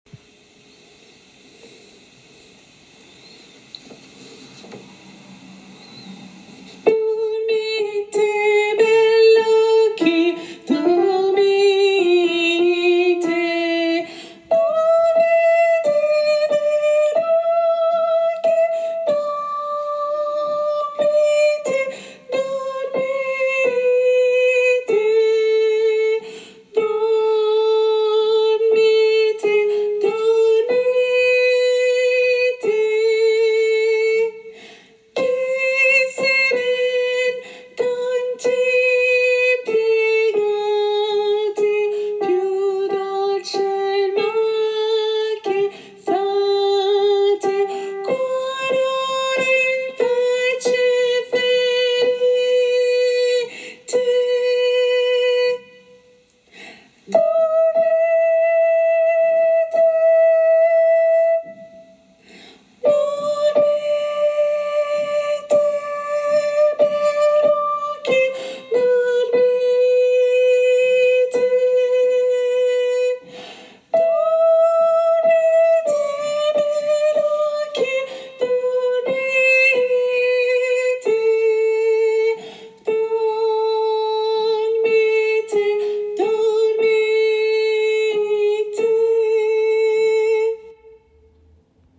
Voix principale et autres voix en arrière-plan
Soprano